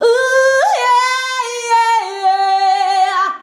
UUUUYEAHH.wav